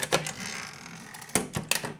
Cambio de línea en una máquina de escribir (maneta)
máquina de escribir
Sonidos: Oficina